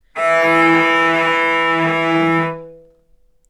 vc_sp-E3-ff.AIF